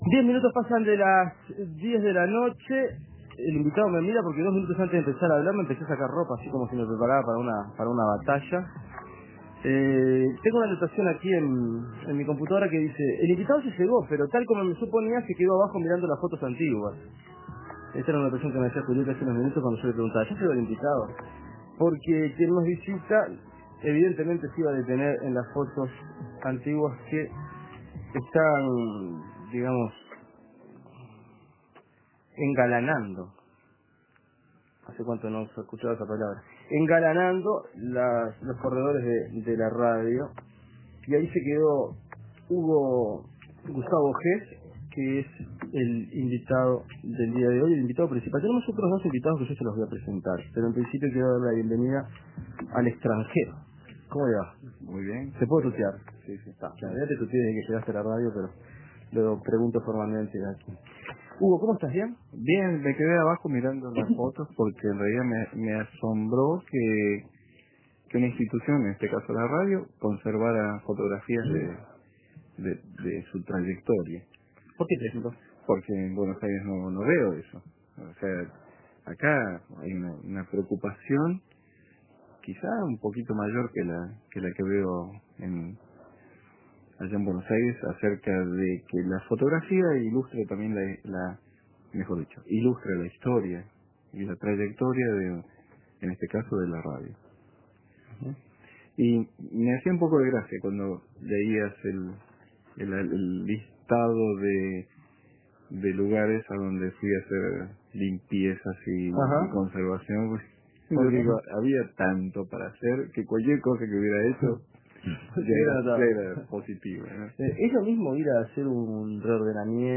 Planetario La conservación fotográfica. Entrevista